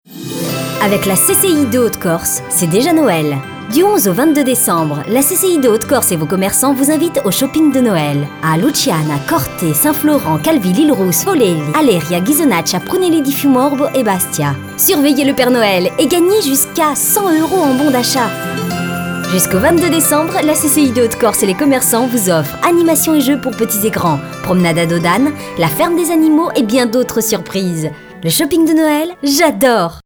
Comédienne et voix off, spécialisée dans le documentaire et les contenus scientifiques grâce à mon bagage universitaire qui me rend particulièrement à l'aise avec le vocabulaire spécifique. Comme je suis comédienne, je me permet de m'amuser avec ma voix et les intentions, écoutez donc mon extrait "pub", les aigüs comme la rapidité ont été obtenus par ma seule voix!
Sprechprobe: Werbung (Muttersprache):